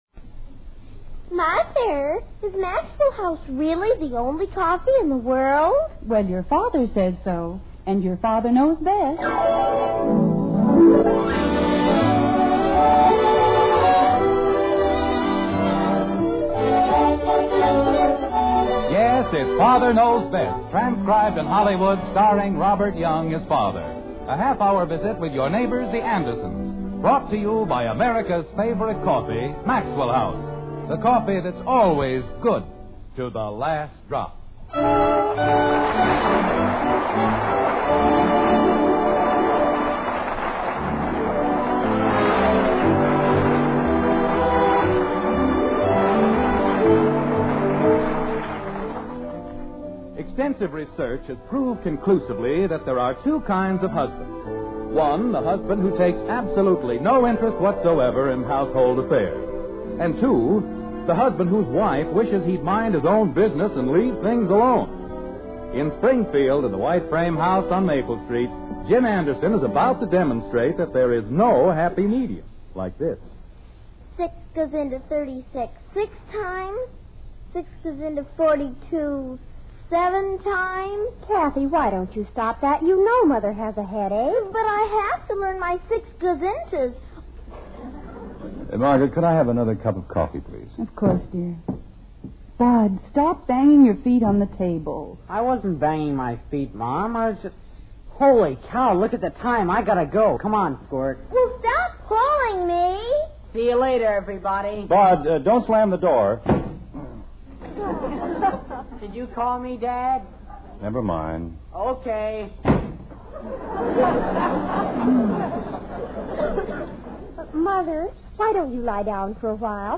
The Father Knows Best Radio Program